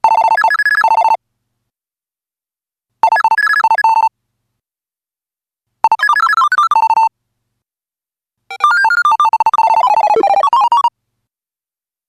EXPERIMENTAL